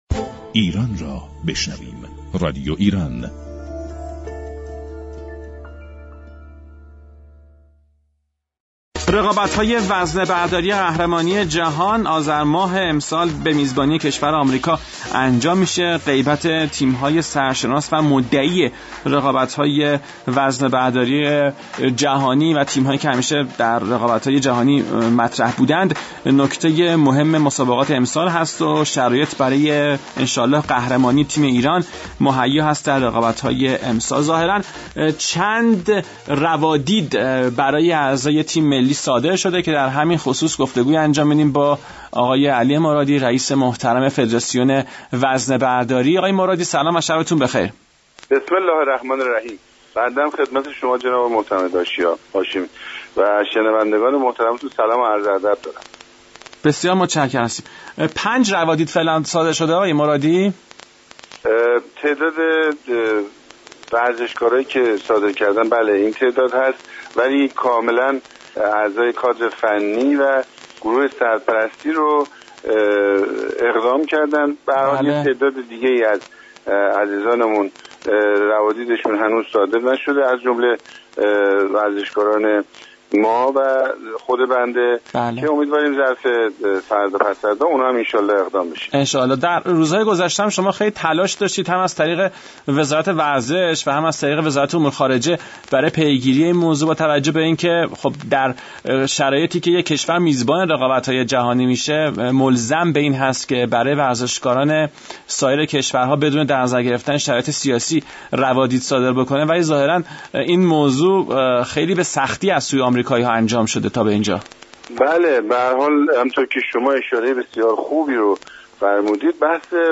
«علی مرادی» رییس فدراسیون وزنه برداری در گفت و گو رادیو ایران گفت: از آنجا كه تلاش های انجام شده از سوی نهادهای مختلف ورزشی هنوز به نتیجه نرسیده، از وزیر ورزش و جوانان درخواست كرده ایم كه به صورت مستقیم وارد بحث با كشور (آمریكا) میزبان شود